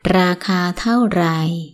raakaa tau _ raii